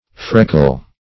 Freckle \Freck"le\ (fr[e^]k"k'l), n. [Dim., from the same root